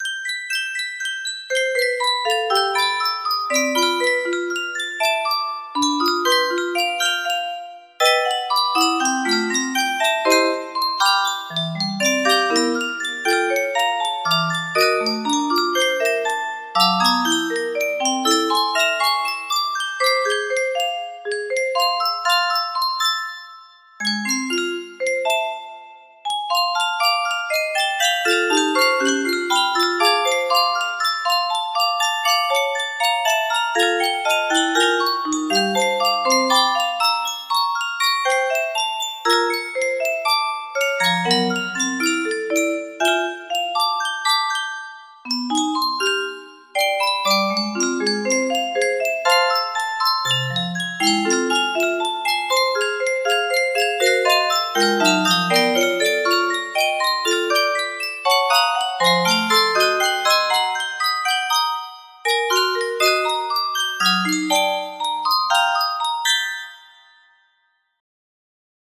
Full range 60
(original composition on midi keyboard)